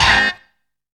SCREECH 2.wav